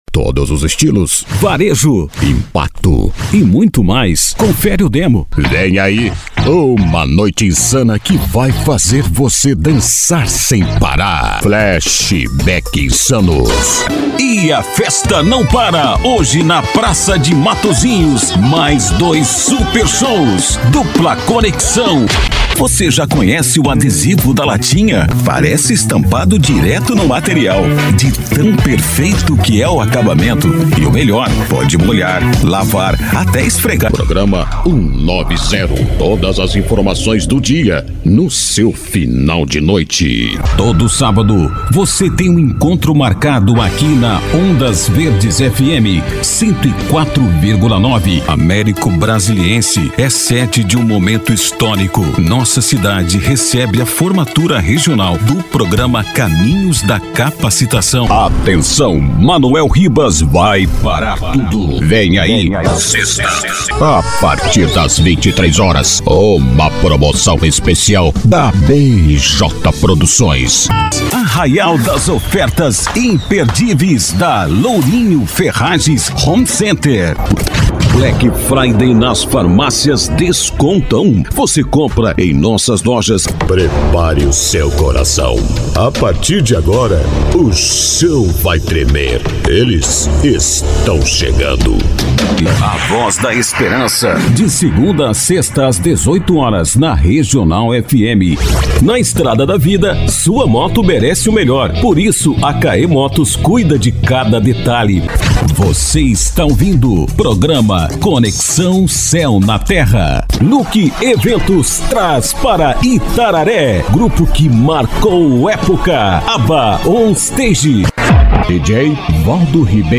Spot Comercial
Vinhetas
Estilo(s):
Impacto
Animada
Caricata